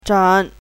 chinese-voice - 汉字语音库
zhen3.mp3